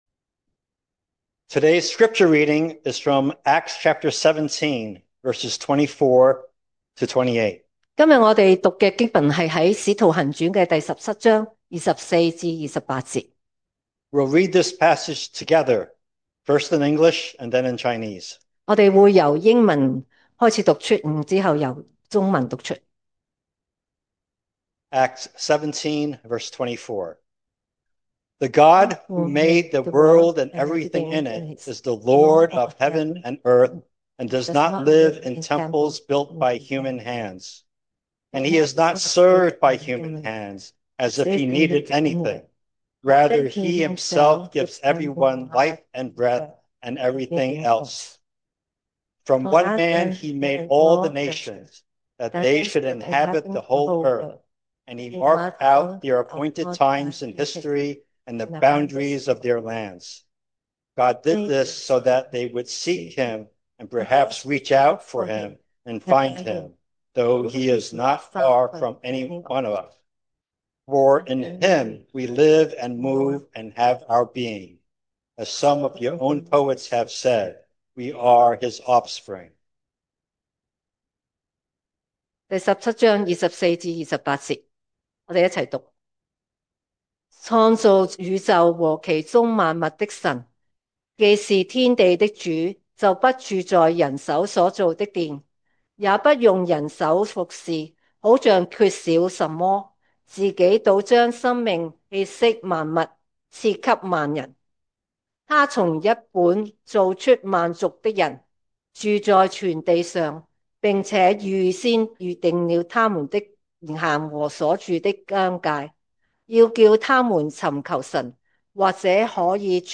sermon audios
Service Type: Sunday Morning